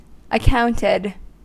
Ääntäminen
Ääntäminen US Haettu sana löytyi näillä lähdekielillä: englanti Accounted on sanan account partisiipin perfekti.